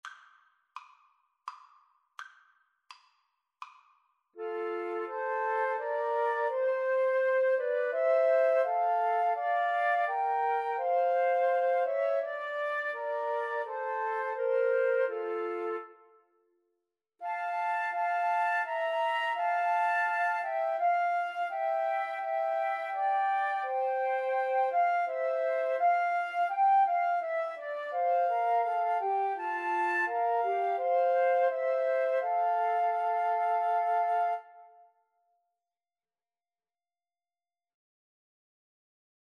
Free Sheet music for Flute Trio
F major (Sounding Pitch) (View more F major Music for Flute Trio )
3/4 (View more 3/4 Music)
Flute Trio  (View more Easy Flute Trio Music)
Traditional (View more Traditional Flute Trio Music)